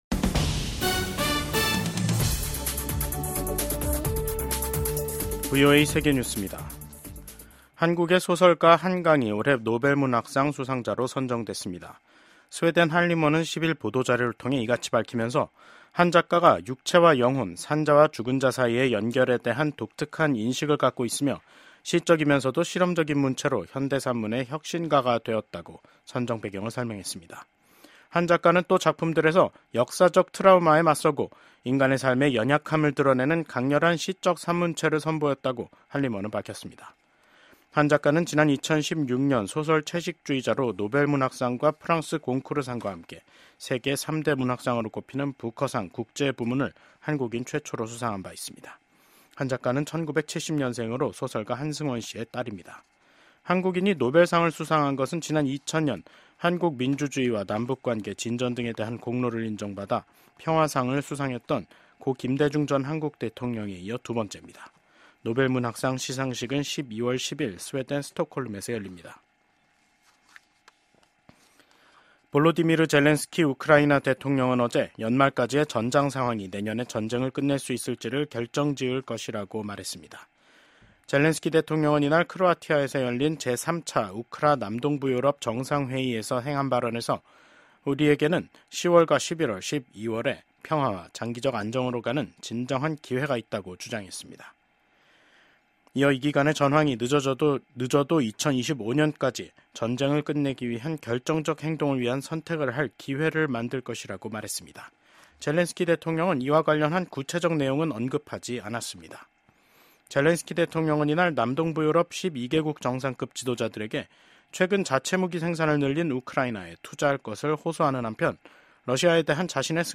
VOA 한국어 간판 뉴스 프로그램 '뉴스 투데이', 2024년 10월 10일 2부 방송입니다. 동남아시아국가연합(아세안) 정상회의에 참석한 윤석열 한국 대통령은 북한 핵을 용납하지 말아야 역내 평화가 보장된다고 밝혔습니다. 한국과 북한이 유엔에서 설전을 벌였습니다. 한국이 핵과 미사일의 완전한 폐기를 촉구하자 북한은 미국의 핵 위협에 맞선 자위권 차원이라고 주장했습니다.